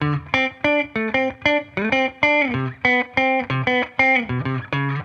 Index of /musicradar/sampled-funk-soul-samples/95bpm/Guitar
SSF_TeleGuitarProc2_95D.wav